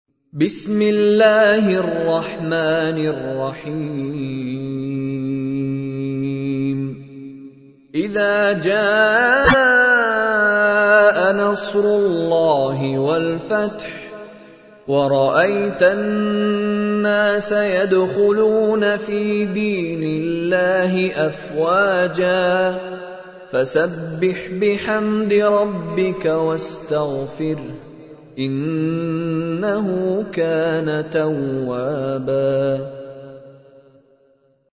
تحميل : 110. سورة النصر / القارئ مشاري راشد العفاسي / القرآن الكريم / موقع يا حسين